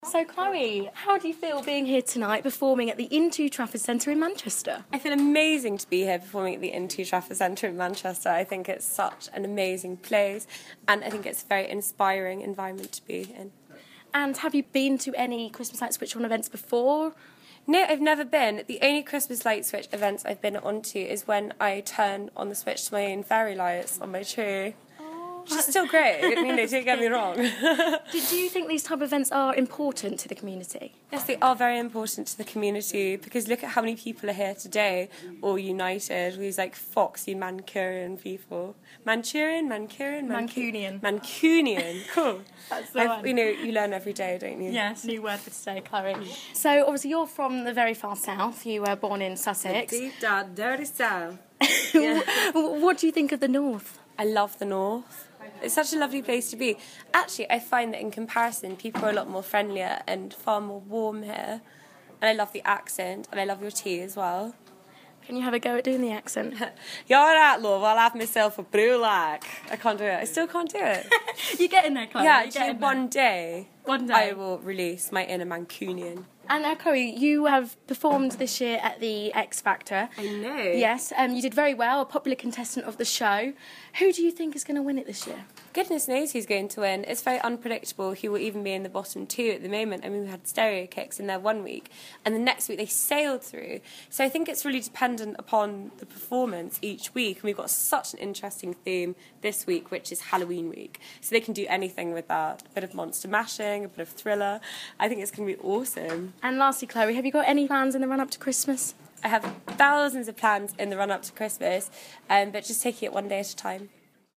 at the intu Trafford Centre Christmas light switch on 2014.